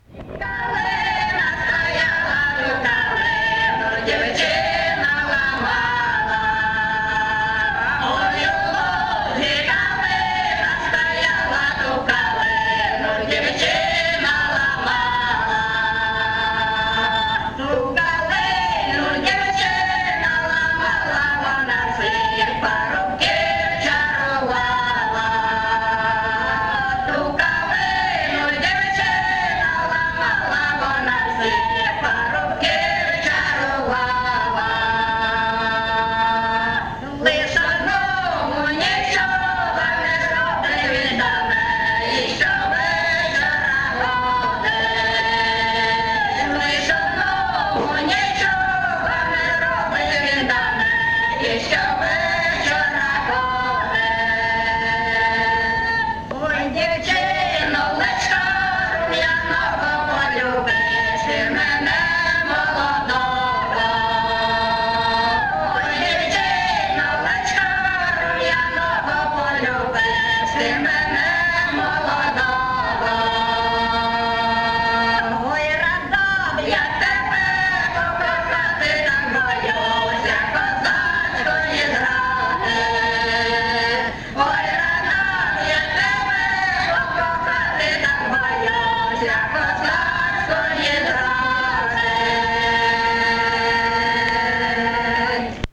ЖанрПісні з особистого та родинного життя
Місце записус. Очеретове, Валківський район, Харківська обл., Україна, Слобожанщина